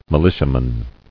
[mi·li·tia·man]